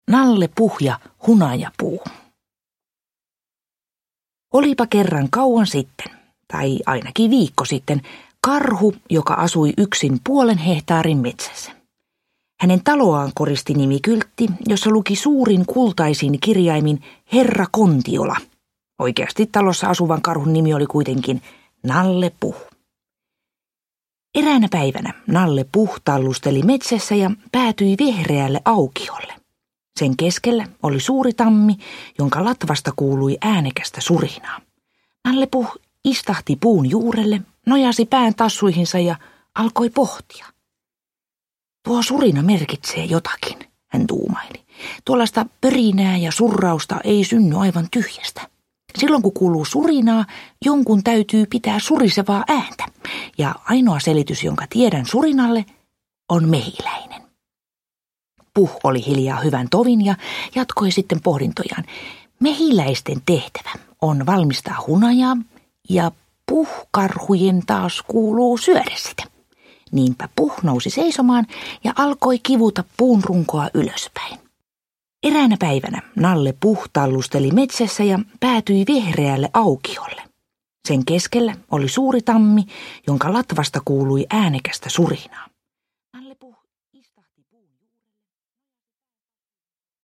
Nalle Puh ja hunajapuu – Ljudbok – Laddas ner